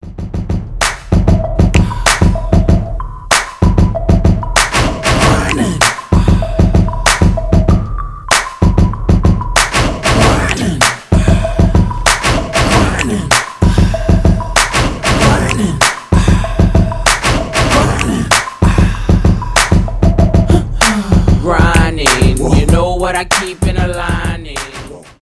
gangsta rap
хип-хоп